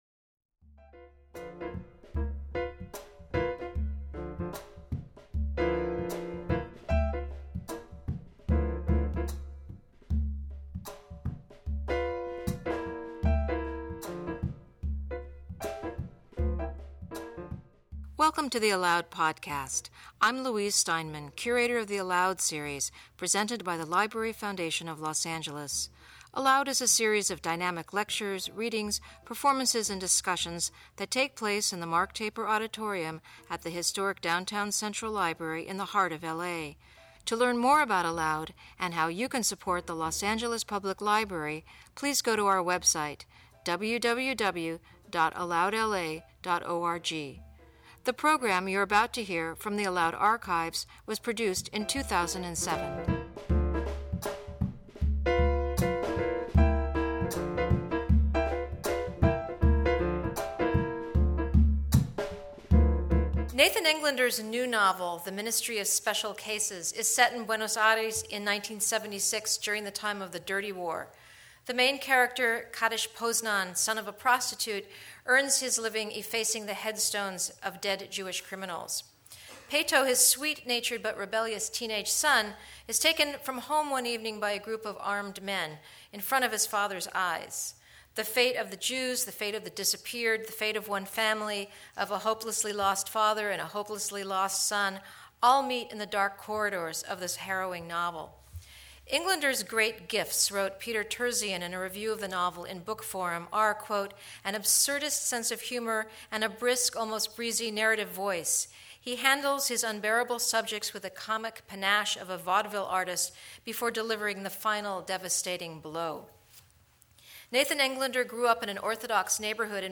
In conversation with writer/producer